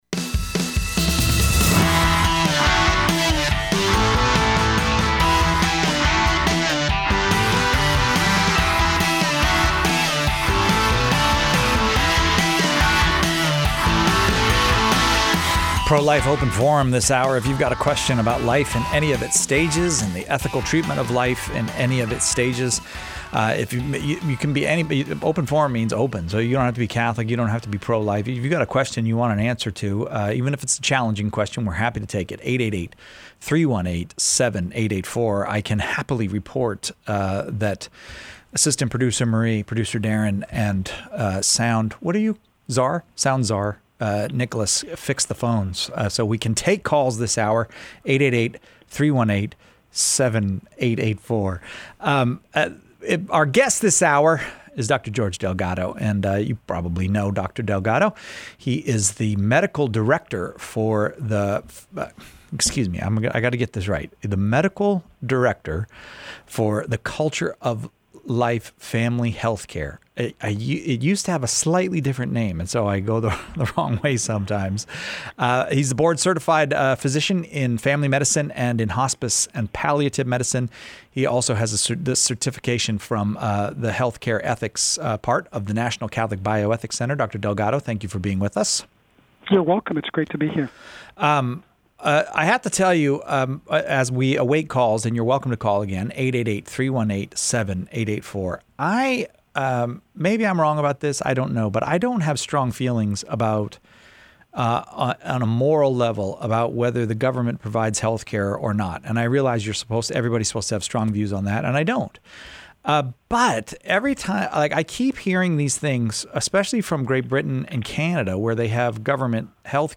Pro-Life Open Forum